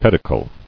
[ped·i·cle]